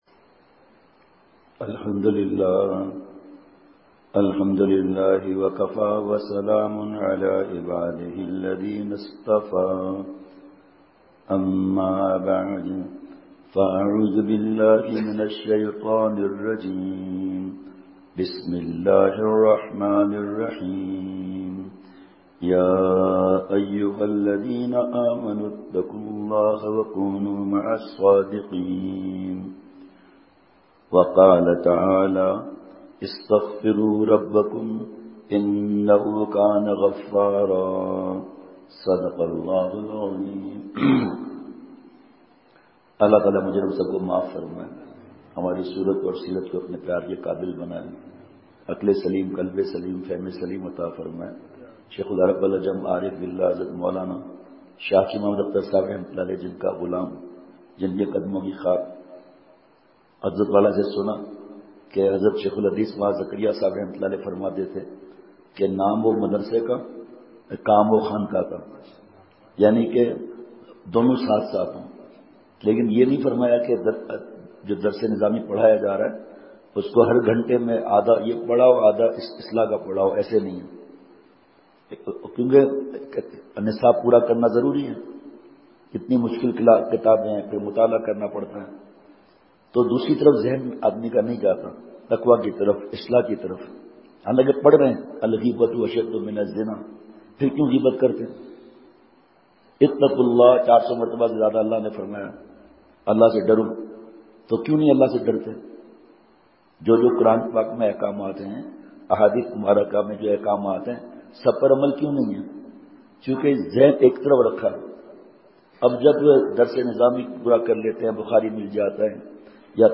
*صبح ساڑے گیارہ بجے بیان:۔*